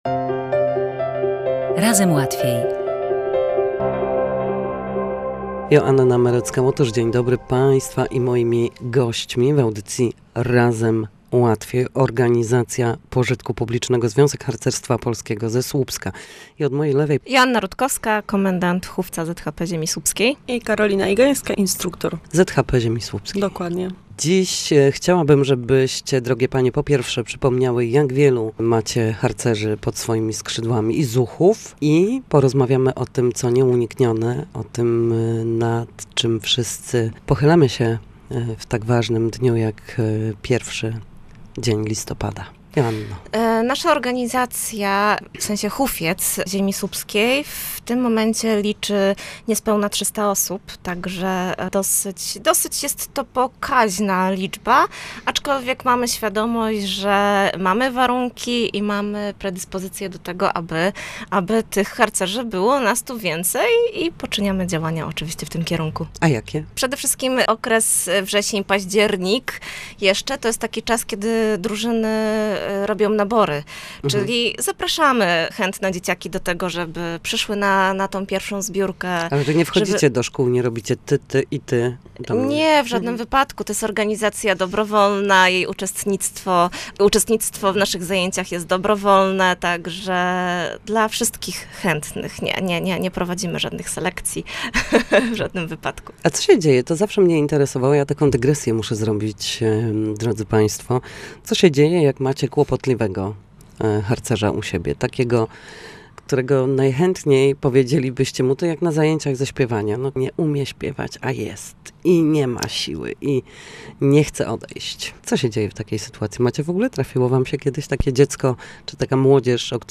W audycji „Razem Łatwiej” harcerze ze Słupska opowiedzą o tym wyjątkowym czasie i druhach, których zabrakło w tym roku.